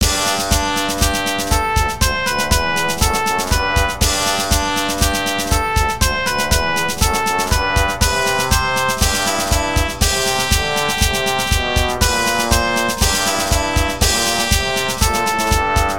どこかの王国の王子が王の冠を受ける華々しい儀式をイメージ。トランペットが喜びのメロディーを奏でます。
ショートループ